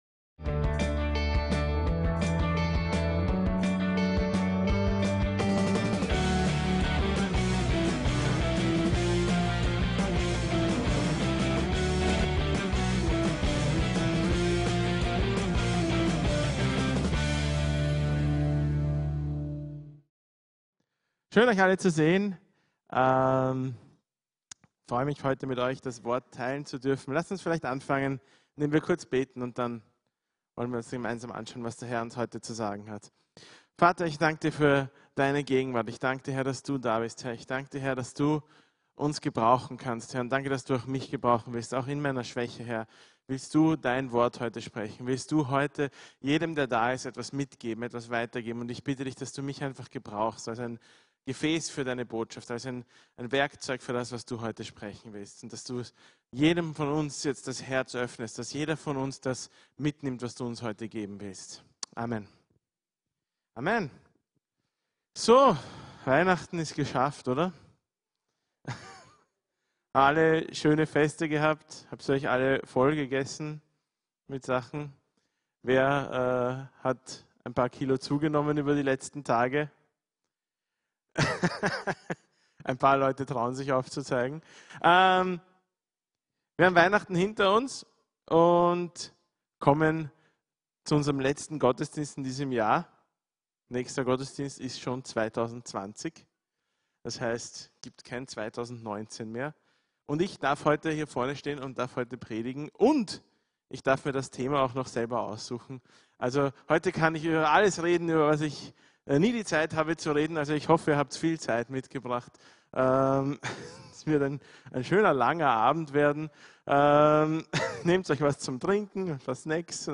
GUTE VORSÄTZE ~ VCC JesusZentrum Gottesdienste (audio) Podcast